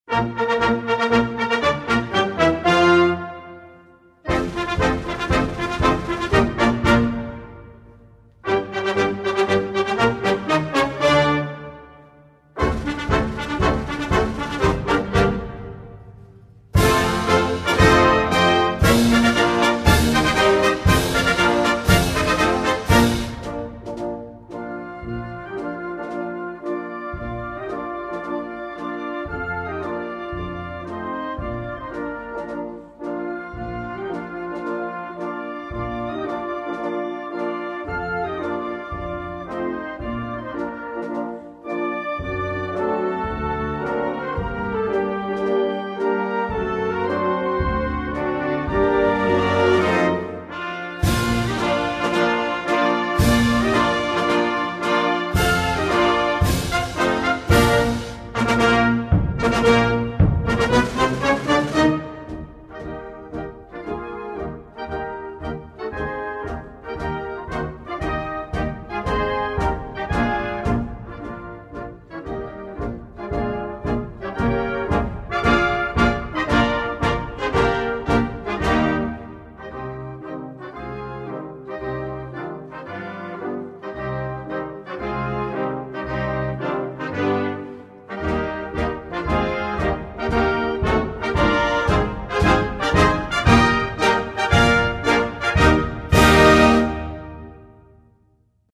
инструментальная версия